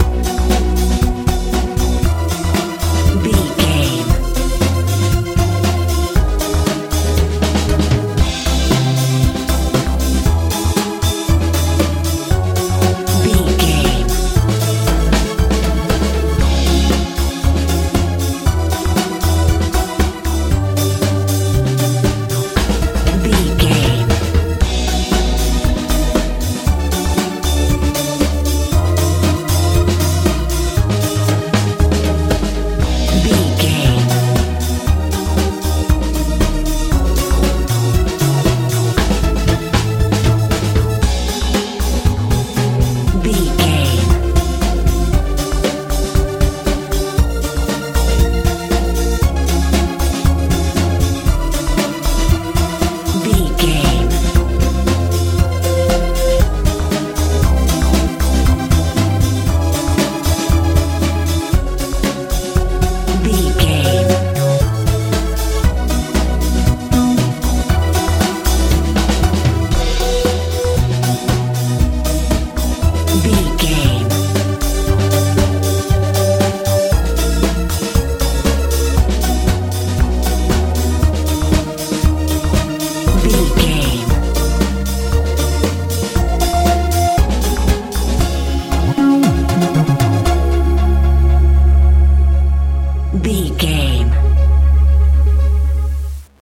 techno feel
Ionian/Major
F♯
energetic
fun
synthesiser
bass guitar
drums
80s
lively